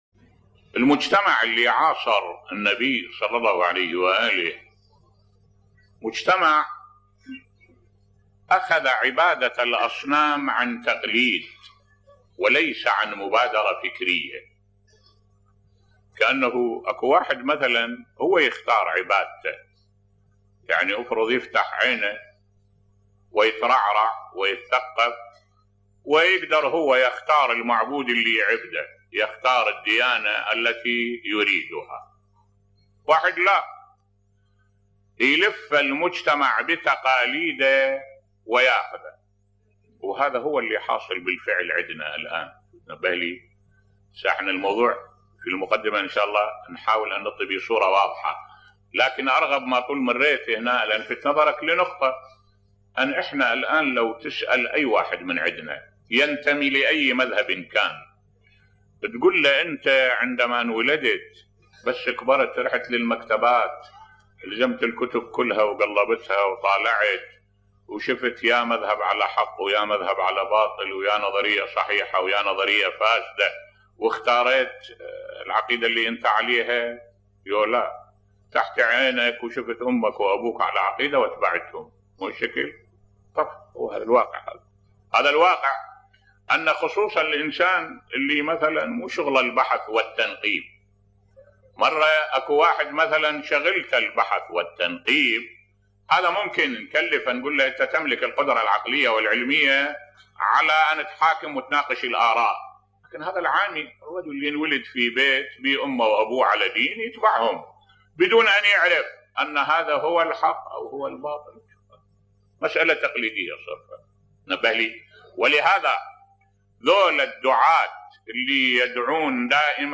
ملف صوتی العبادة لا تلغي العقل بصوت الشيخ الدكتور أحمد الوائلي